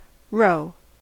ROH) or hard roe, is the fully ripe internal egg masses in the ovaries, or the released external egg masses, of fish and certain marine animals such as shrimp, scallop, sea urchins and squid.
En-us-roe.ogg.mp3